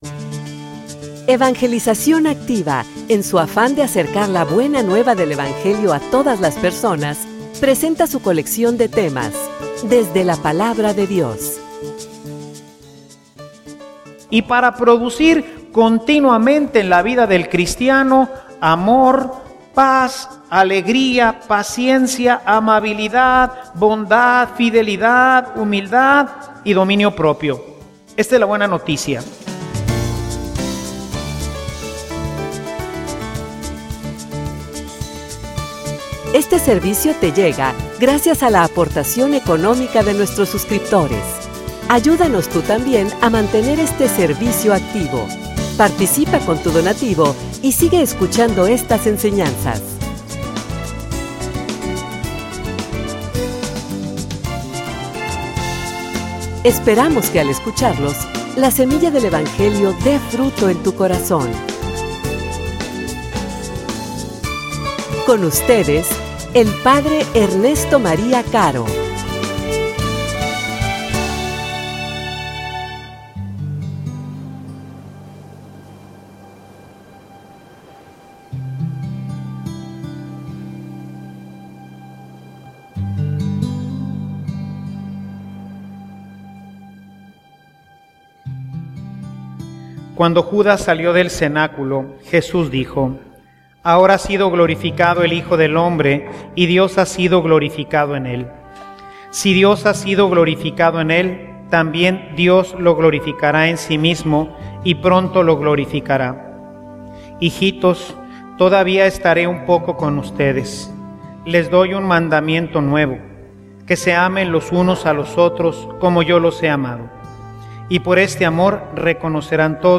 homilia_No_aflojes_el_paso.mp3